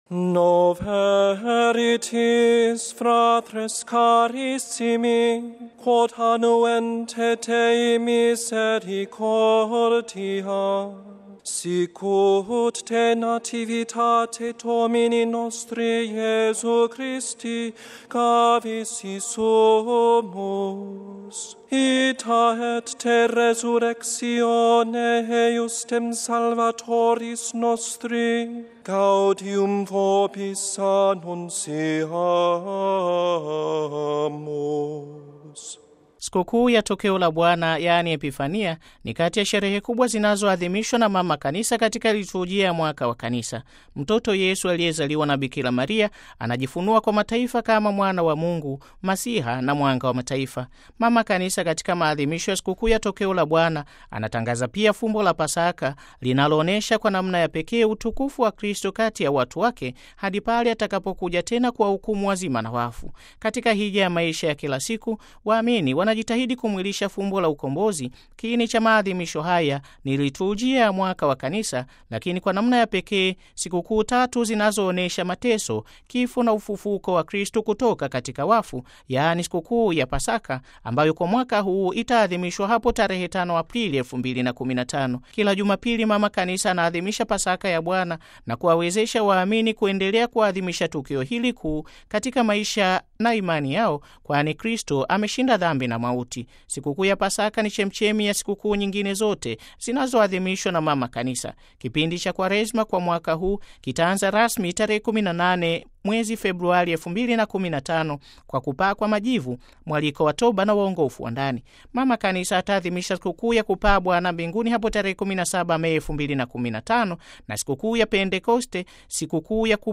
ndiye aliyesoma ombi kwa lugha ya Kiswahili katika maadhimisho ya Siku kuu ya Tokeo la Bwana, Kwenye Kanisa kuu la Mtakatifu Petro mjini Vatican, tarehe 6 Januari 2015.